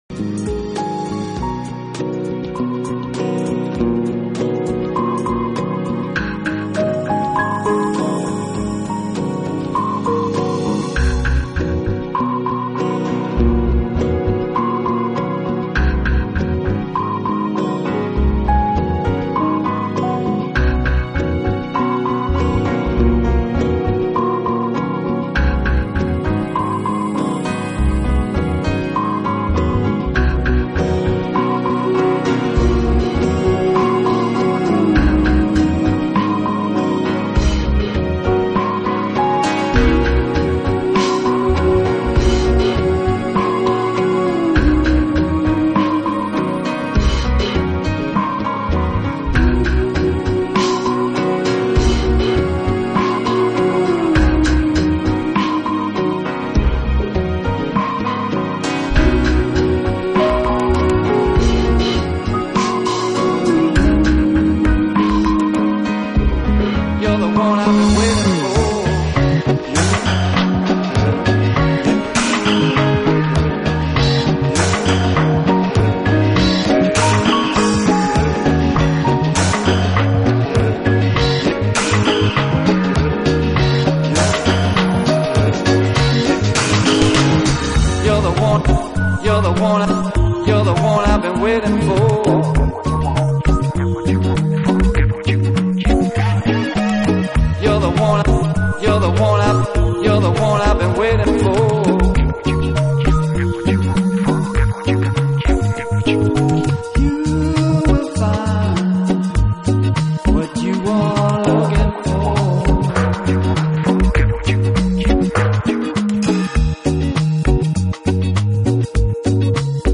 【新世纪音乐】
Style: Ambient, Downtempo